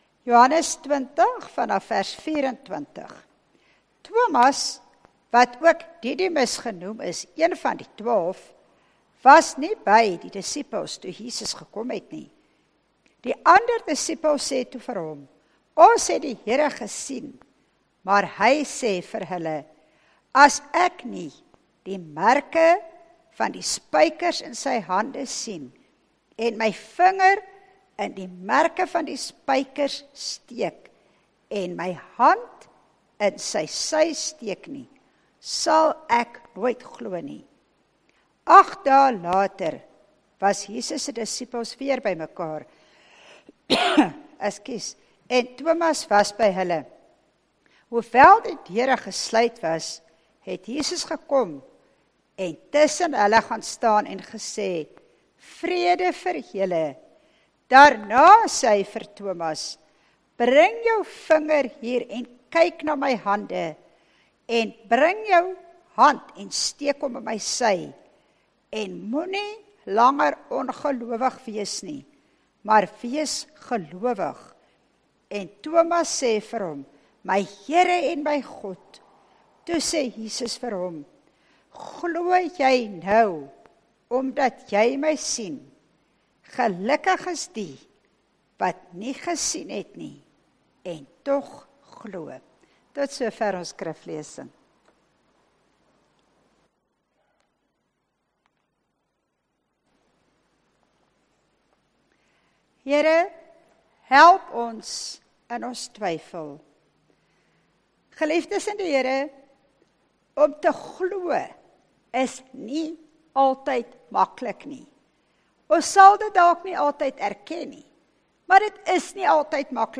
*Erediens 5 September 2021*